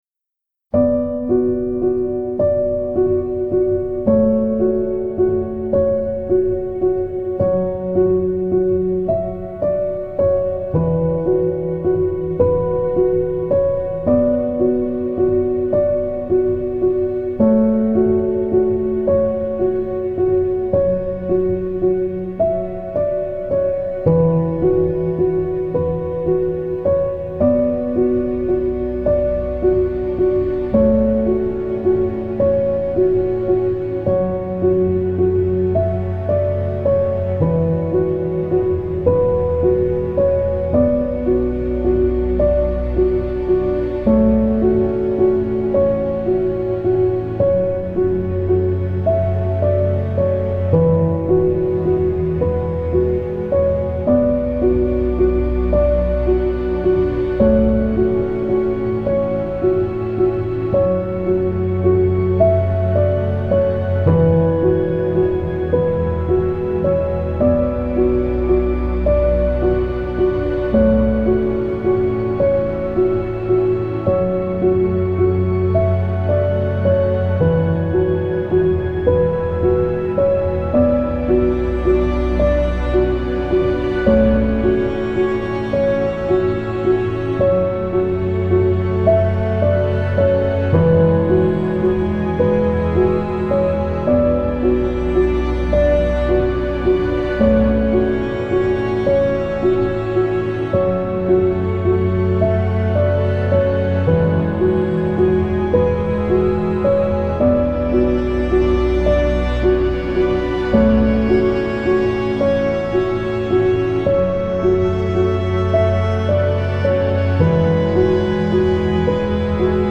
آرامش بخش
پیانو , عصر جدید , عمیق و تامل برانگیز